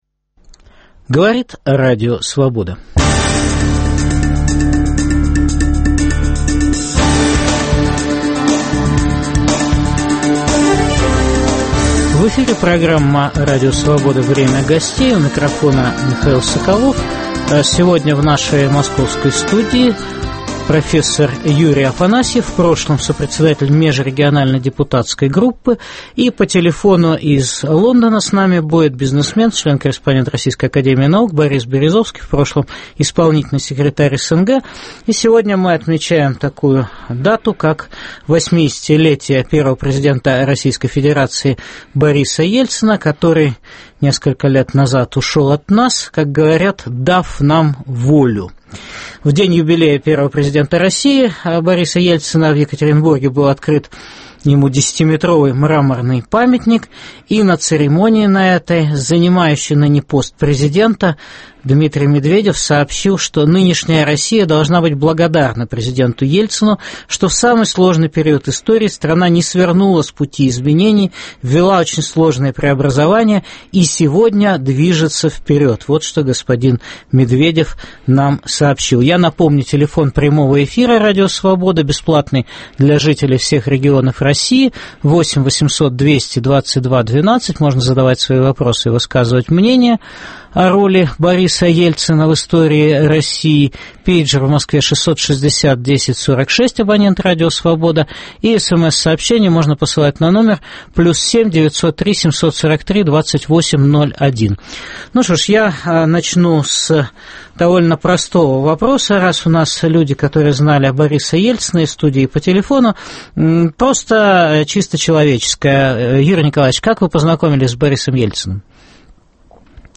Отмечаем 80-летие Бориса Ельцина. В программе дискутируют член-корреспондент РАН Борис Березовский и профессор Юрий Афанасьев.